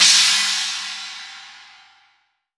8SA CYMB.WAV